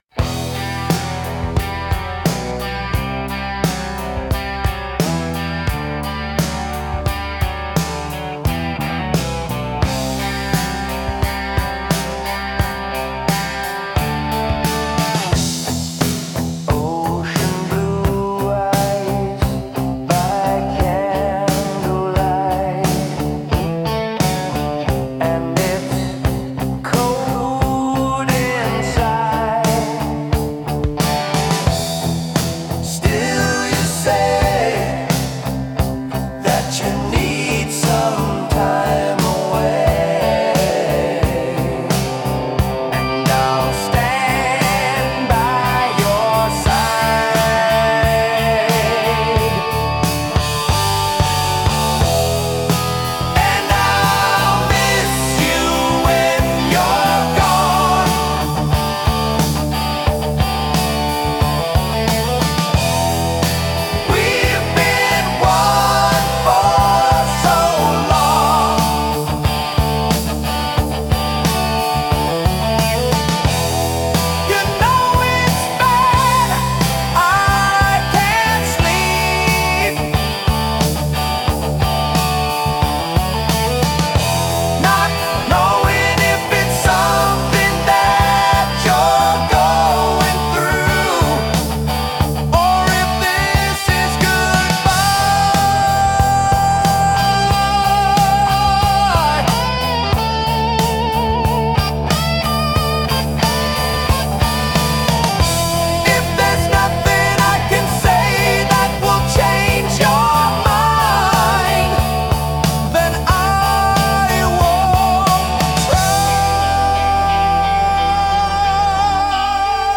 • Genre: 1970s Classic Rock / Power Ballad
• Tempo (BPM): Approximately 72 BPM